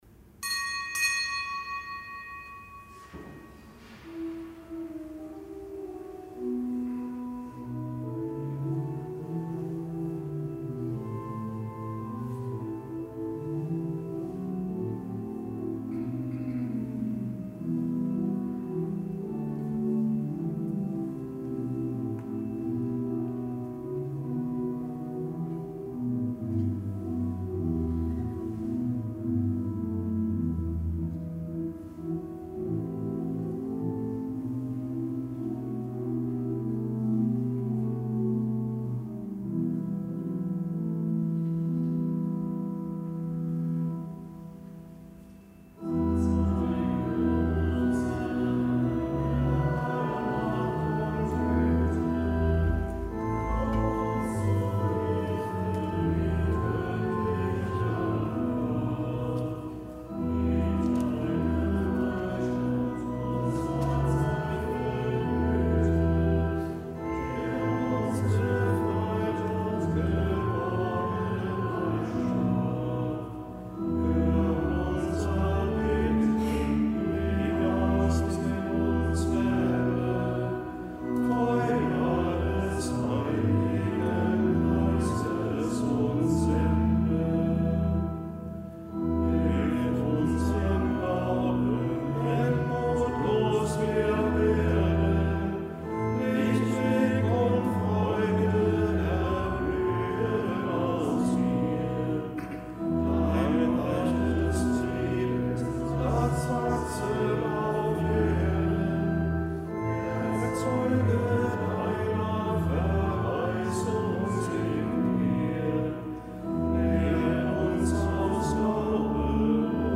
Kapitelsmesse am Freitag der zweiten Fastenwoche
Kapitelsmesse aus dem Kölner Dom am Freitag der zweiten Fastenwoche, am Herz-Jesu-Freitag.